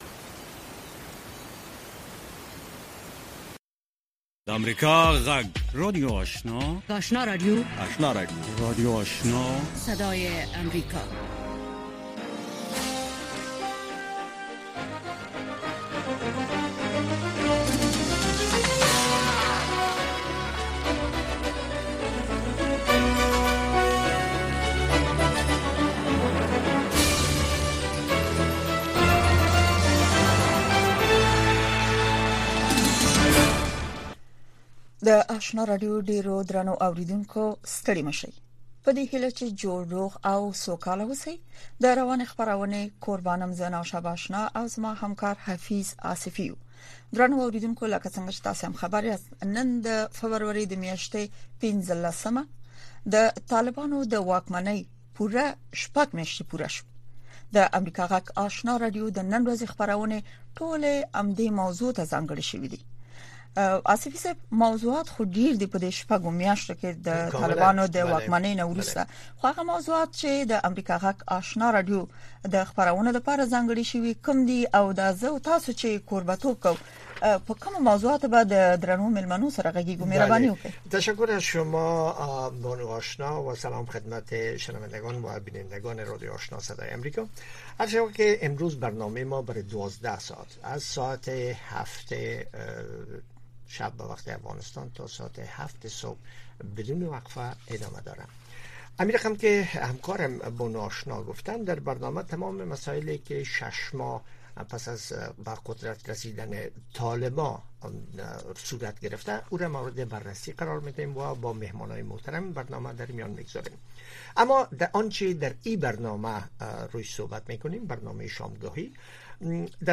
لومړنۍ ماښامنۍ خبري خپرونه